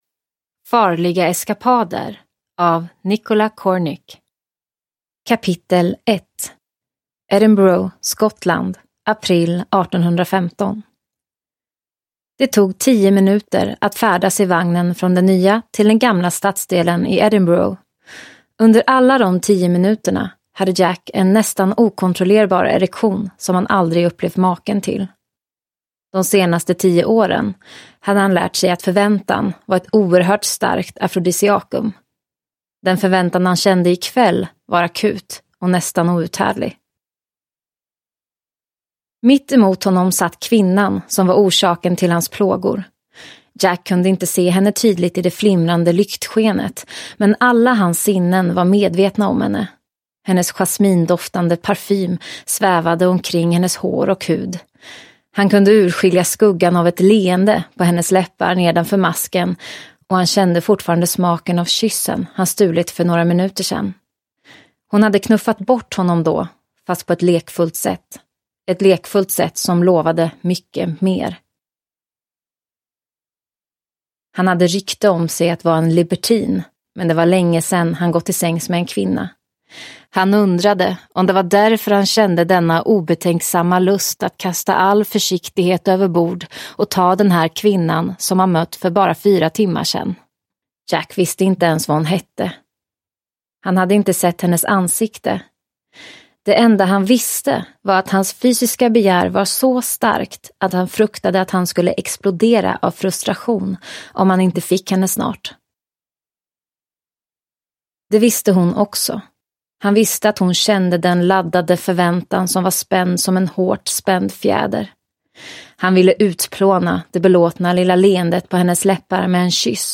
Farliga eskapader – Ljudbok – Laddas ner